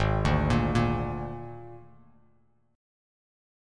G#sus2b5 chord